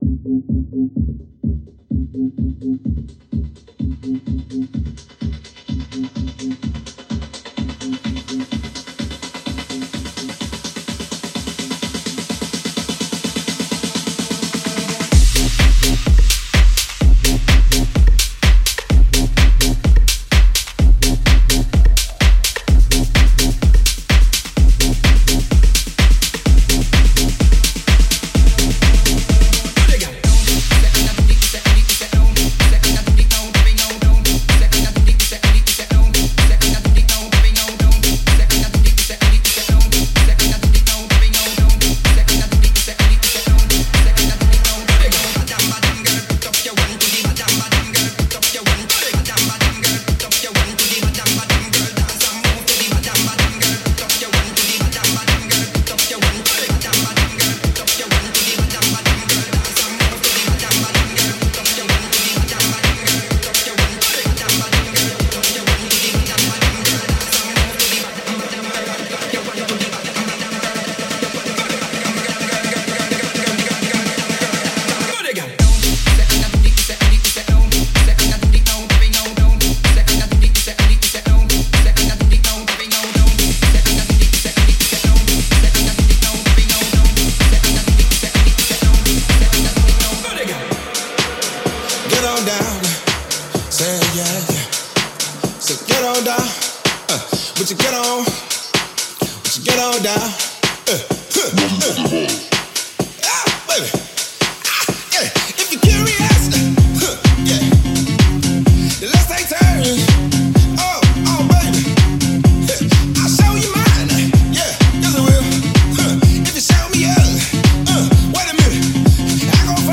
DJ Mixes and Radio Show